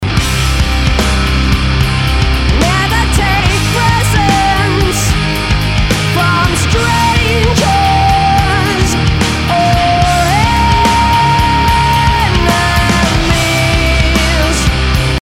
higher-alarm.mp3